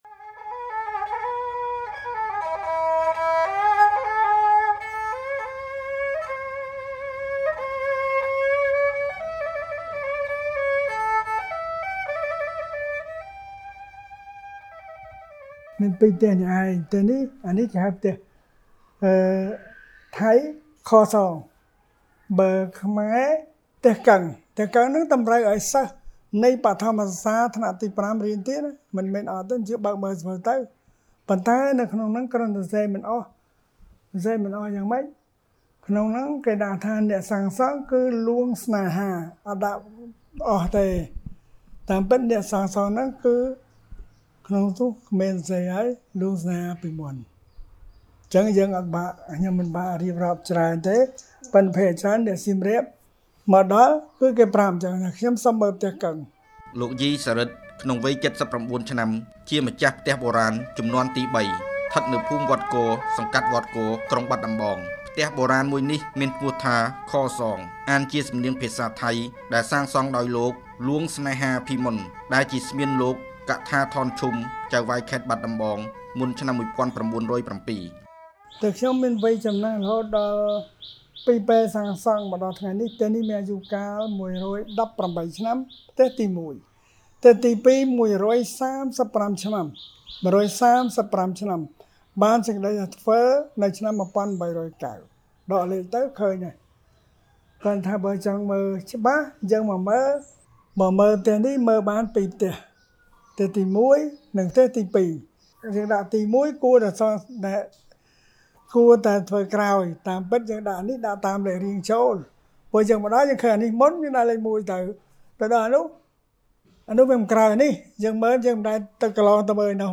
បទយកការណ៍